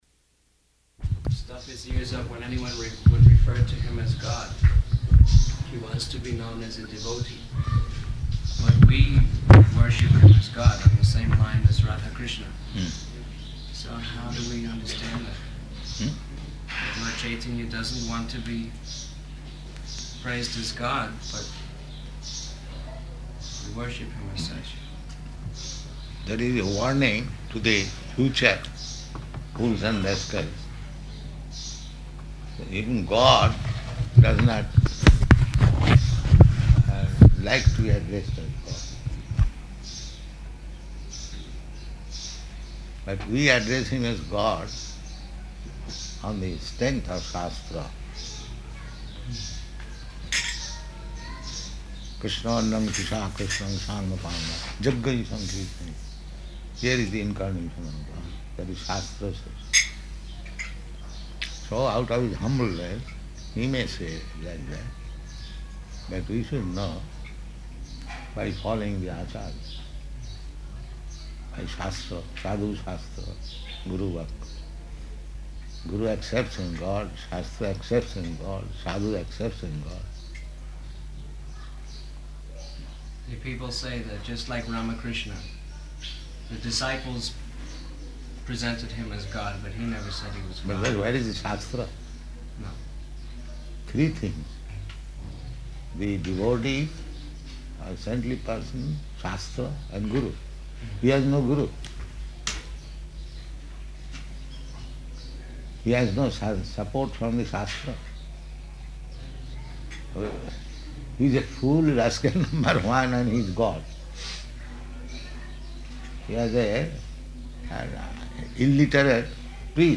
Conversation with Devotees
-- Type: Conversation Dated: March 31st 1975 Location: Māyāpur Audio file